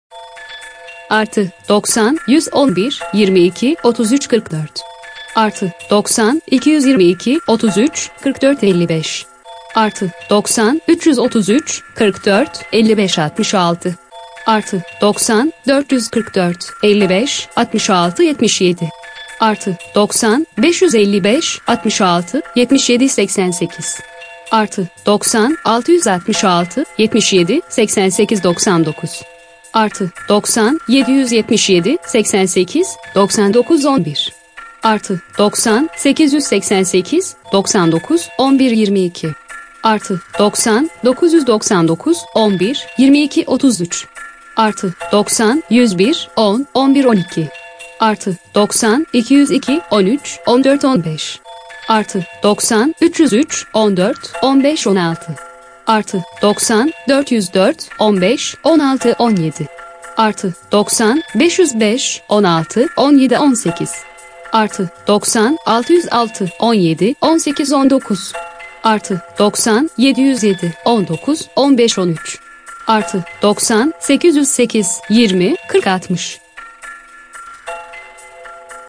Я вам записала номера турецких телефонов и заставила робота их произнести...
"Ничё не знаю",- ваша задача - на бумажке с первого раза умудриться записать то, что эта виртуальная тётенька говорит.
Не потому что цифр не знаем, а потому что тётенька слишком быстро их произносит, а я слишком медленно думаю.